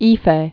(ēfā)